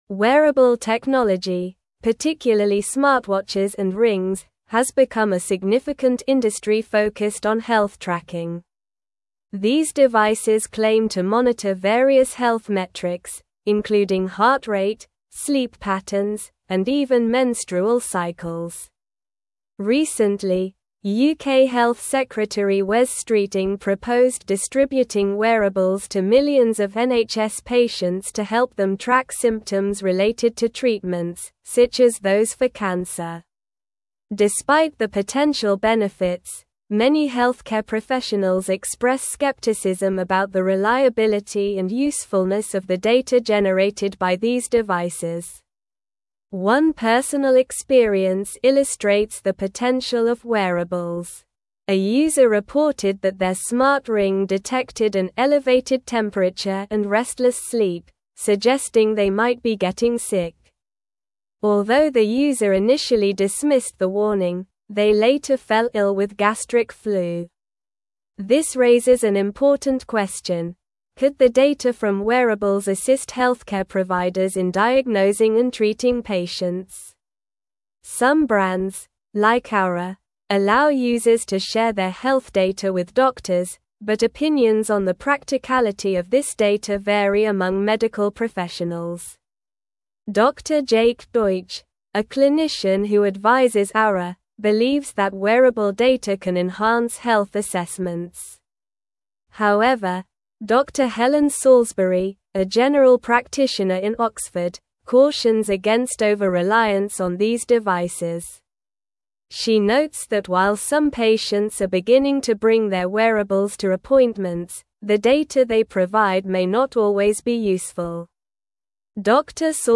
Slow
English-Newsroom-Advanced-SLOW-Reading-Wearable-Technologys-Impact-on-Healthcare-Benefits-and-Concerns.mp3